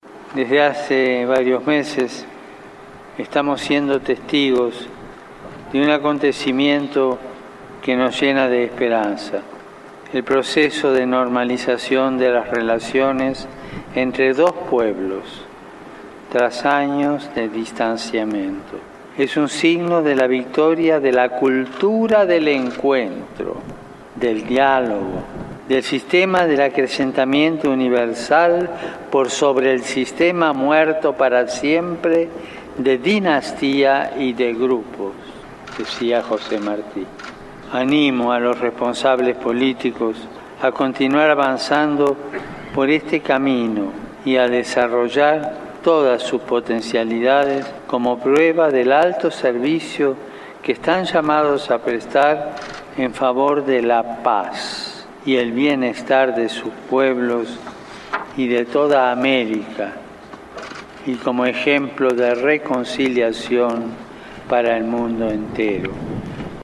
Discurso del Papa a su llegada a Cuba